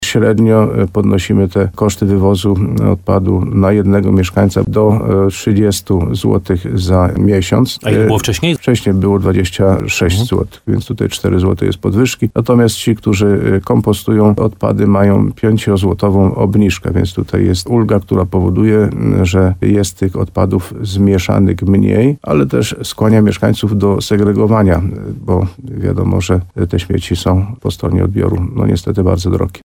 Wójt gminy Ropa Karol Górski przyznaje, że podwyżka jest podyktowana wynikiem ostatniego przetargu.